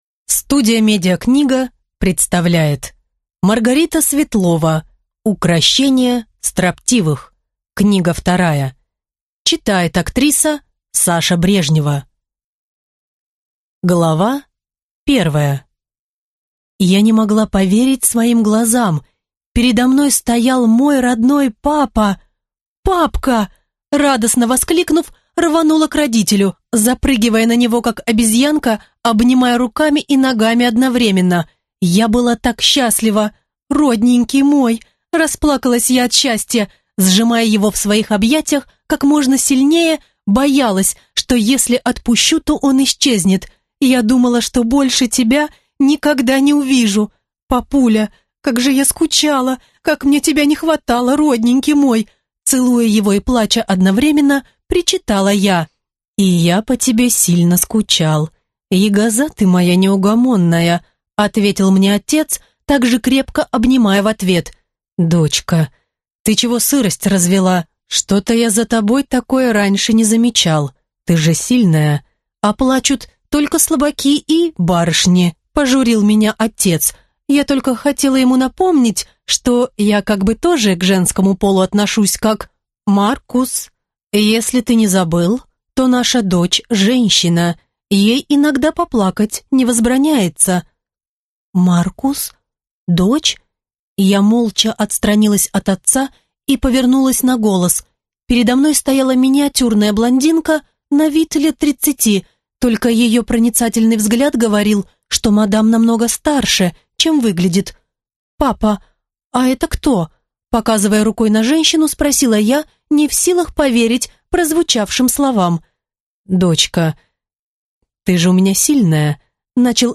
Аудиокнига Укрощение строптивых. Книга 2 | Библиотека аудиокниг